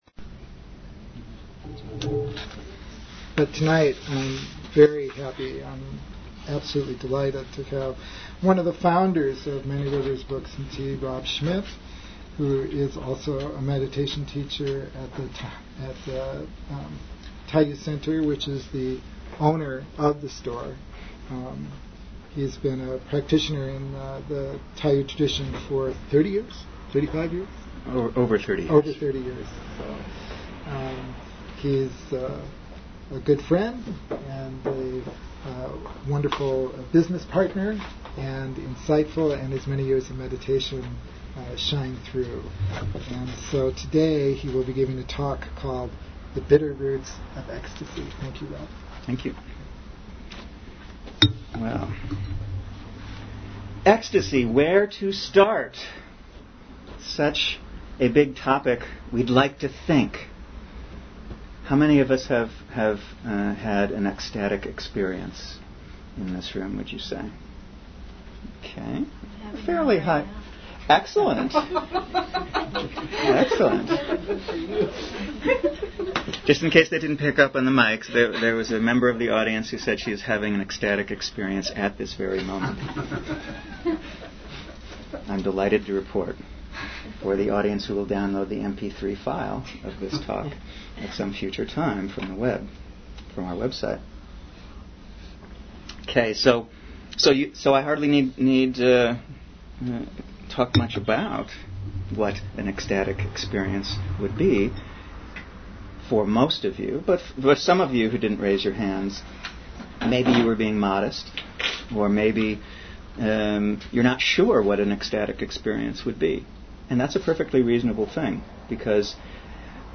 Archive of an event at Sonoma County's largest spiritual bookstore and premium loose leaf tea shop.
Join us for a talk about the practice of bringing the bitter together with the sweet at the heart of consciousness.